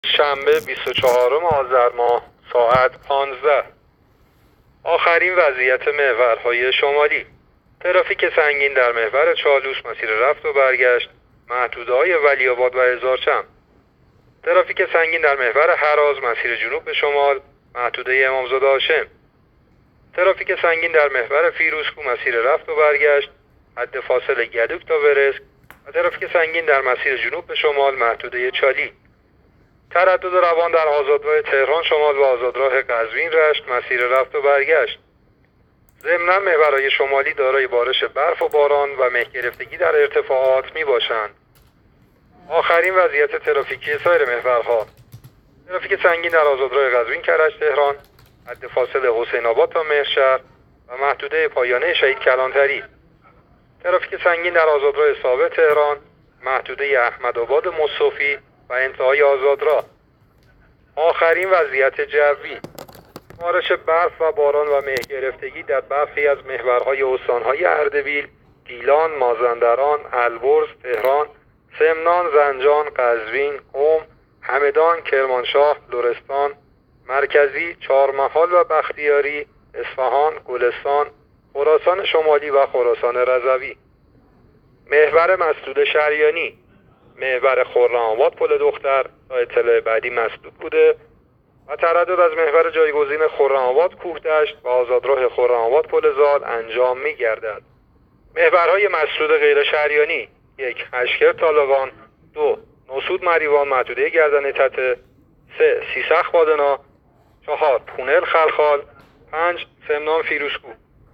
گزارش رادیو اینترنتی از آخرین وضعیت ترافیکی جاده‌ها تا ساعت ۱۵ بیست‌وچهارم آذر؛